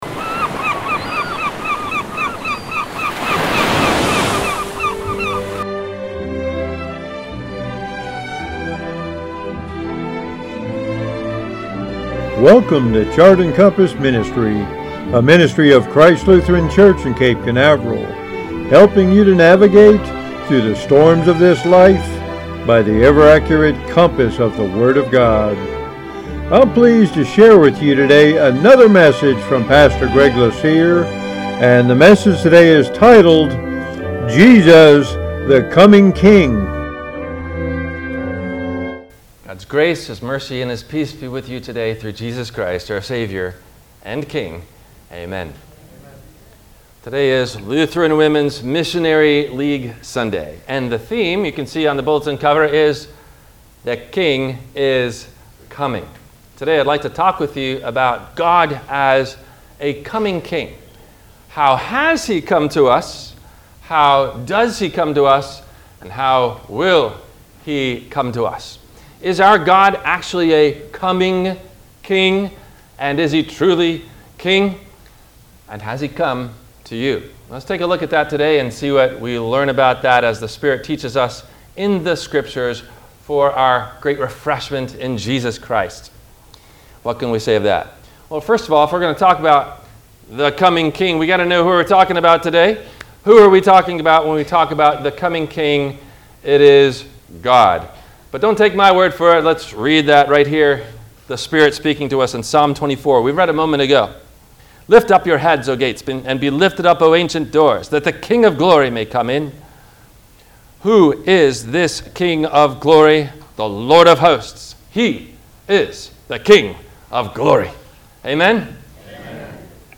WMIE Radio – Christ Lutheran Church, Cape Canaveral on Mondays from 12:30 – 1:00